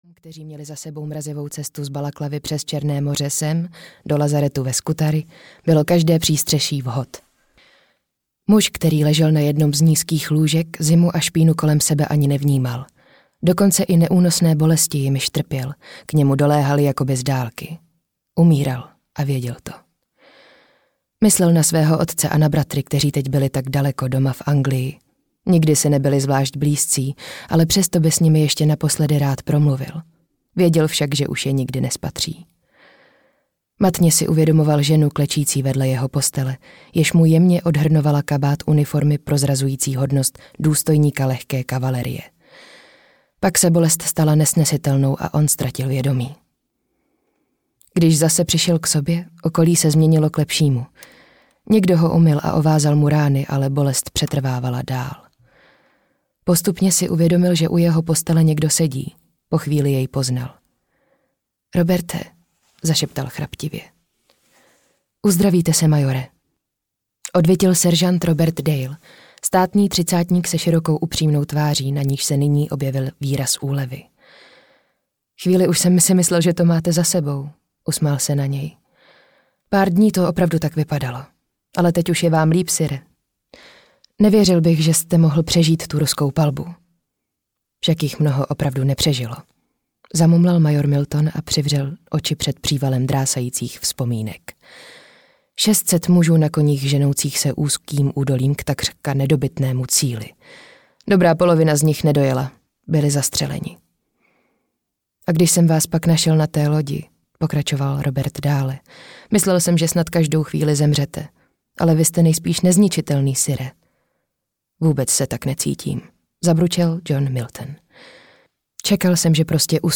Ráj na zemi audiokniha
Ukázka z knihy
raj-na-zemi-audiokniha